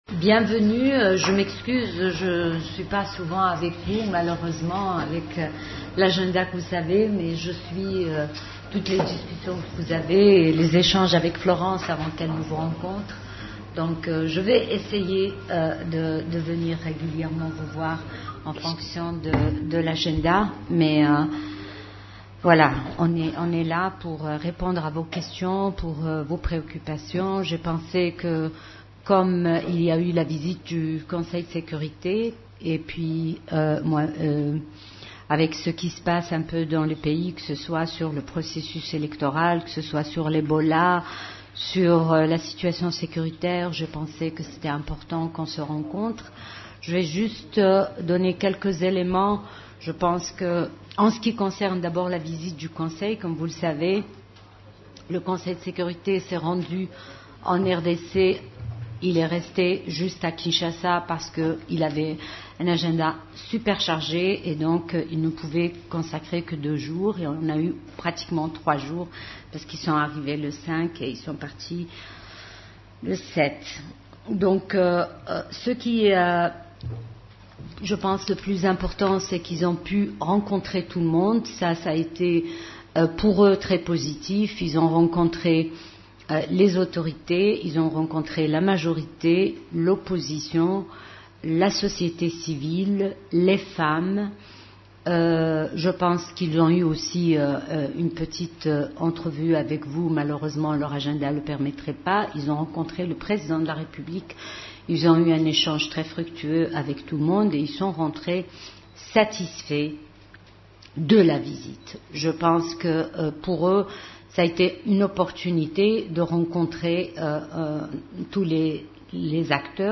La Représentante spéciale du Secrétaire général de l’ONU en RDC a tenu une conférence de presse mardi 16 octobre 2018 au quartier général de la MONUSCO à Kinshasa. Pendant une heure, Mme Leila Zerrougui a répondu aux journalistes sur des questions aussi variées que ses bons offices, les élections, la machine à voter, la lutte contre les ADF à Beni, le prix nobel de la paix attribué au Dr Mukwege.